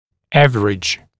[‘ævərɪʤ][‘эвэридж]средний, усредненный